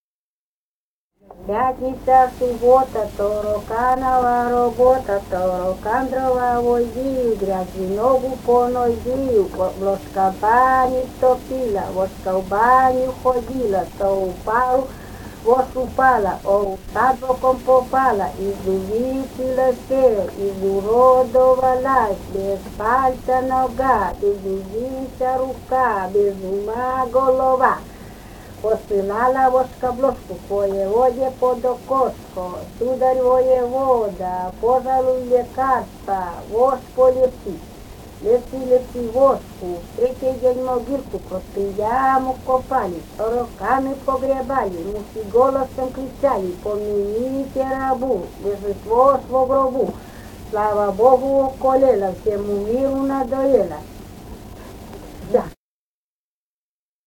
«Пятница-суббота» (потешка).
Вологодская область, д. Малая Тигинского с/с Вожегодского района, 1969 г. И1129-17